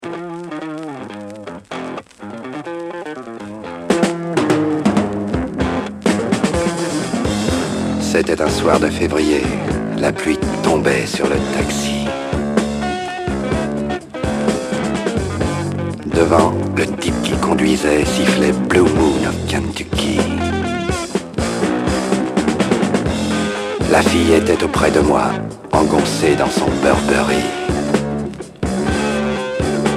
Freakbeat